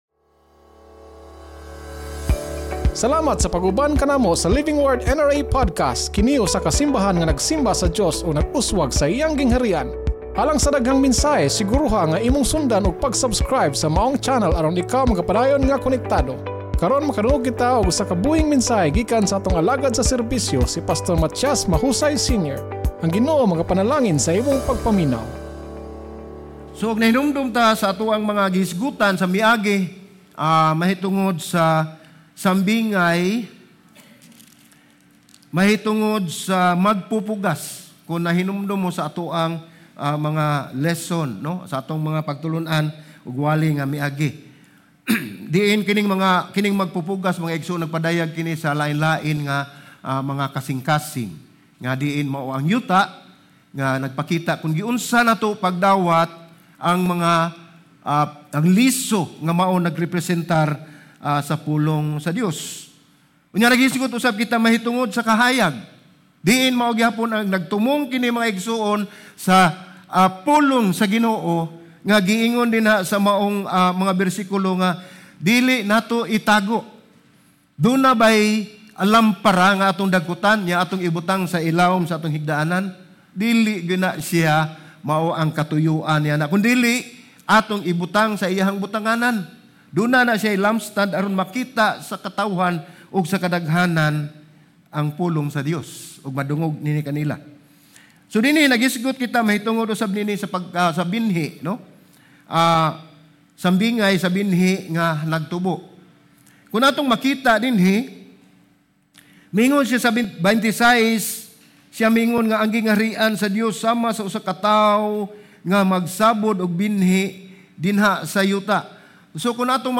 Sermon Title: ANG KATINGALAHAN NGA PAGLAMBO SA GINGHARIAN SA DIOS Sermon Text: MARCOS 4:26-34 (ANG BIBLIA)